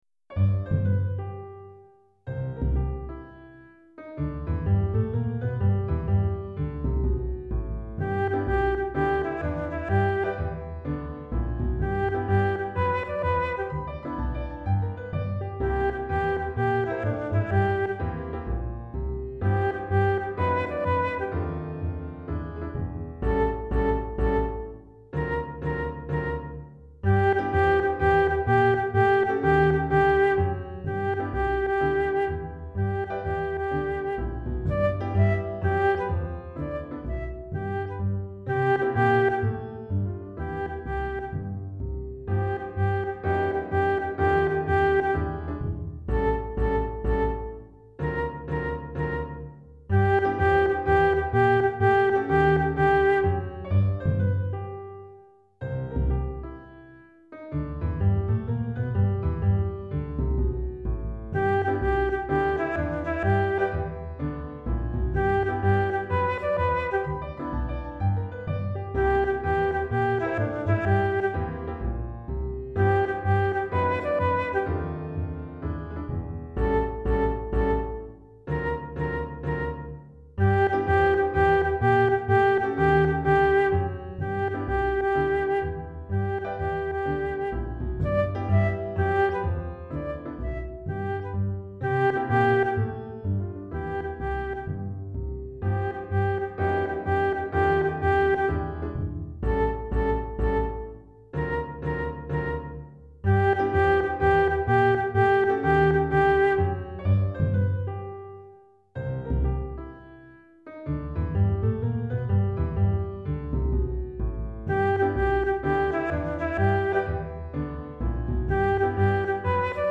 Chorale d'Enfants (8 à 11 ans) et Piano